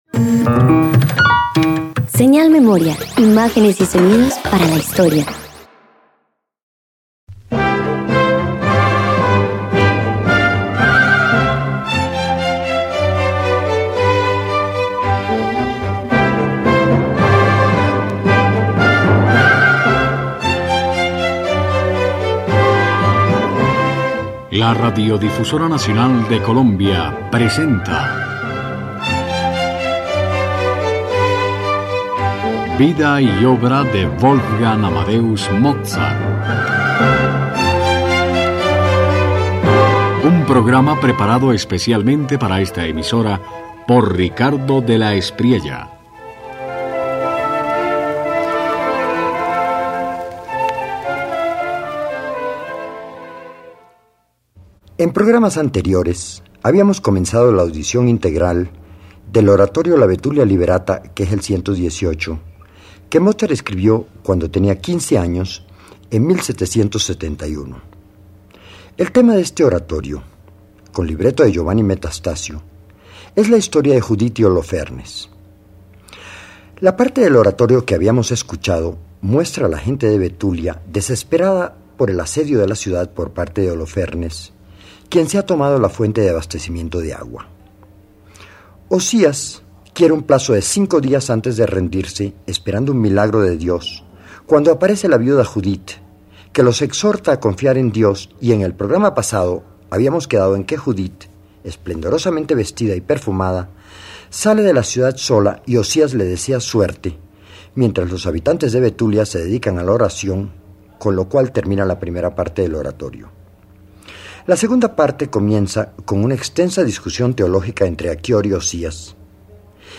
Mozart, con apenas quince años, enfrenta la profunda tensión entre fe y razón: Osías discute con Aqueor como si la teología fuese teatro de ideas. Entre recitativos sobrios y arias de aliento sensual, la música modela dudas y certezas.
047 Oratorio La Betulia Liberata Parte II_1.mp3